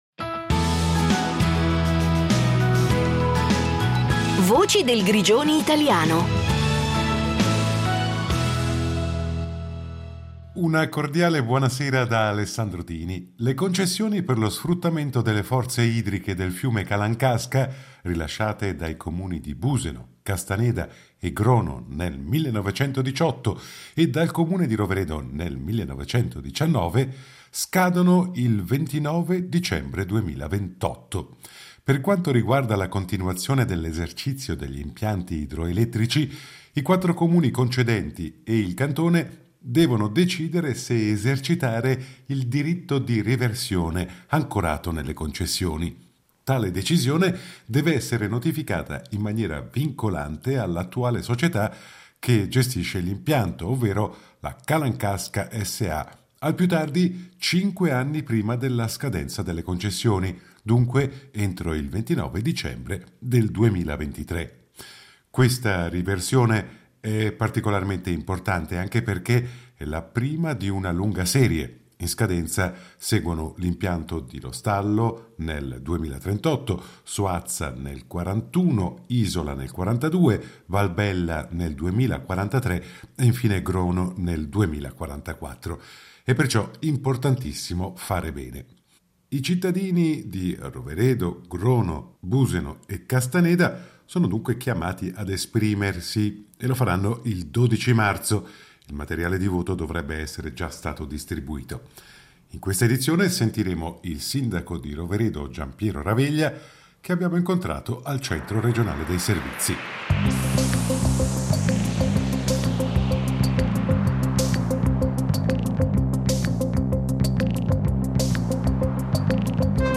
In questa edizione sentiamo il sindaco di Roveredo Giampiero Raveglia che abbiamo incontrato al Centro regionale dei servizi.